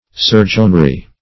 surgeonry - definition of surgeonry - synonyms, pronunciation, spelling from Free Dictionary Search Result for " surgeonry" : The Collaborative International Dictionary of English v.0.48: Surgeonry \Sur"geon*ry\ (s[^u]r"j[u^]n*r[y^]), n. Surgery.
surgeonry.mp3